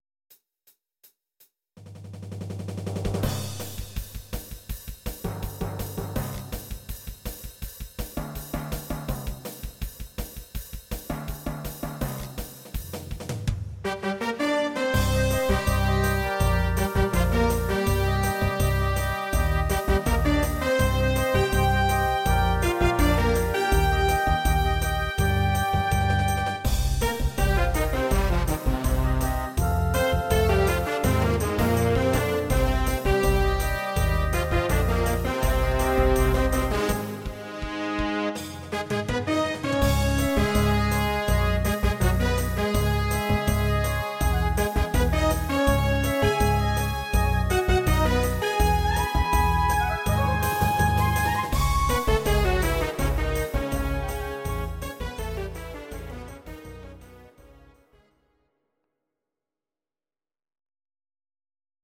These are MP3 versions of our MIDI file catalogue.
Please note: no vocals and no karaoke included.
Your-Mix: Oldies (2907)